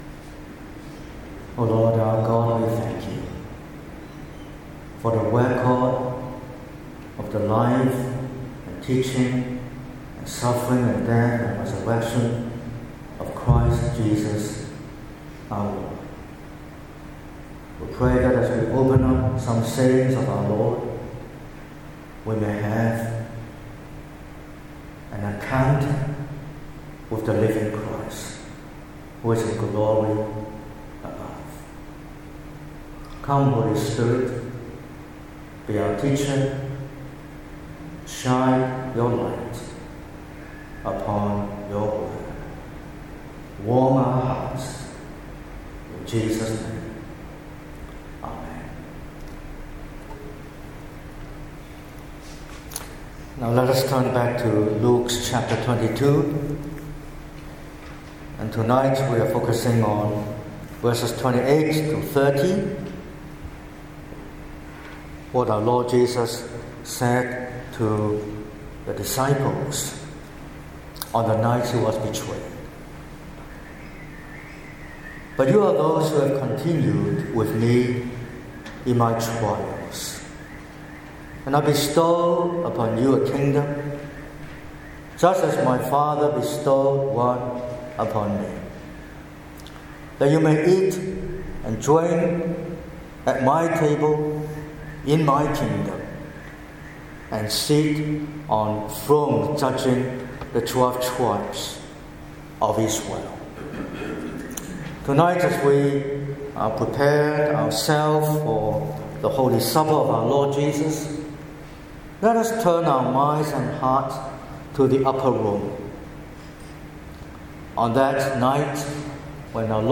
08/03/2026 – Evening Service: A Kingdom Bestowed?